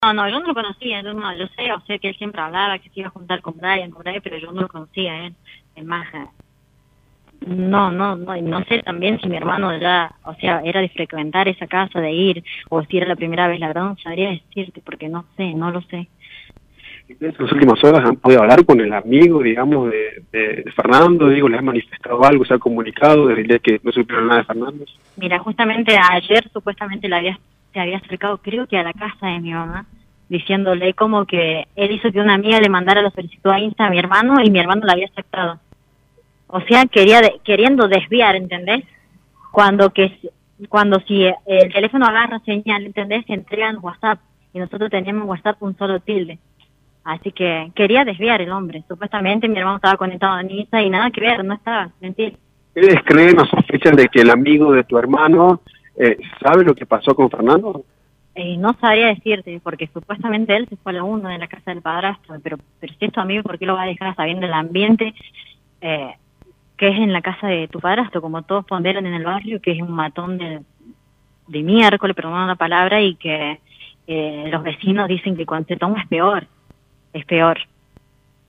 🎙 Entrevista